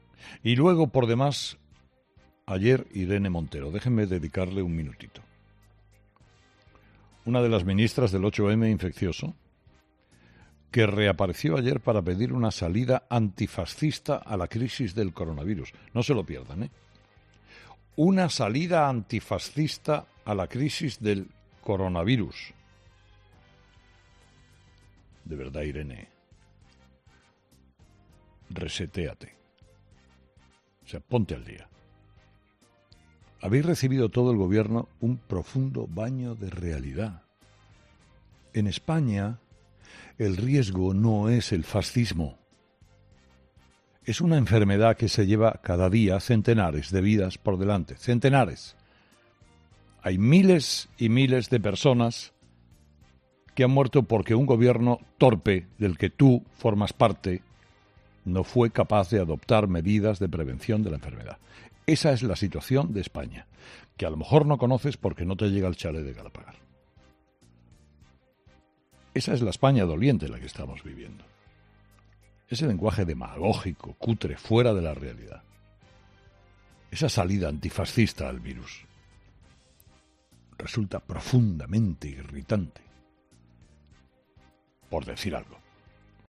En su monólogo de este lunes, Carlos Herrera ha rebatido las palabras de Montero.